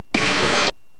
スプラッシュ